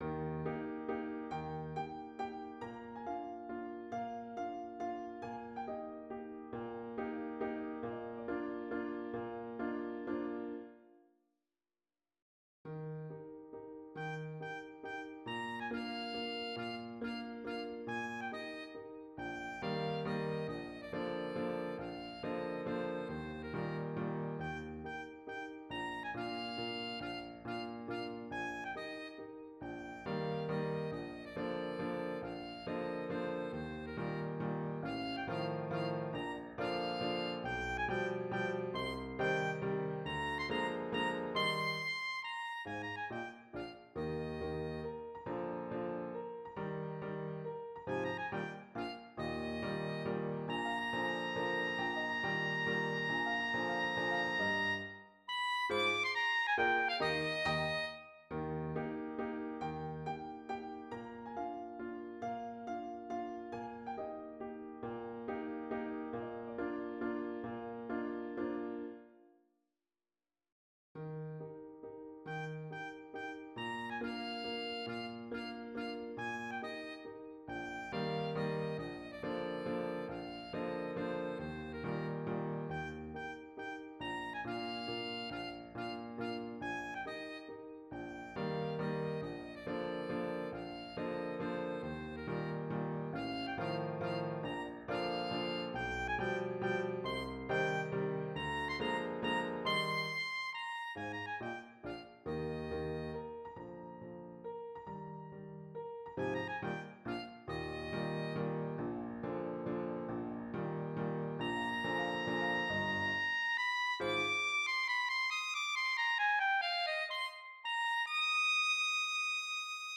Voicing: Alto Saxophone and Piano